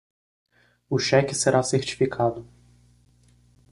Pronounced as (IPA)
/seʁ.t͡ʃi.fiˈka.du/